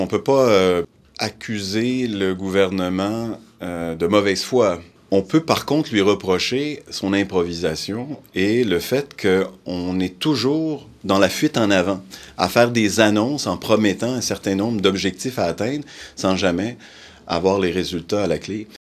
La porte-parole nationale du PQ, Méganne Perry-Mélançon, a tenu un point de presse ce matin à l’Assemblée nationale, en compagnie de Joël Arseneau.